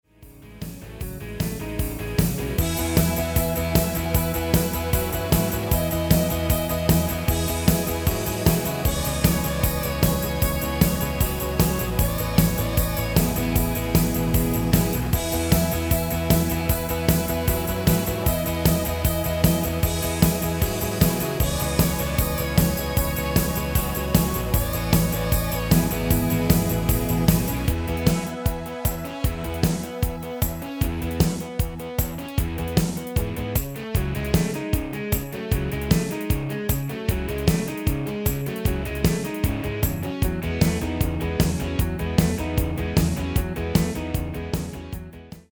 Demo/Koop midifile
Genre: Pop & Rock Internationaal
Toonsoort: E
- Géén vocal harmony tracks
Demo's zijn eigen opnames van onze digitale arrangementen.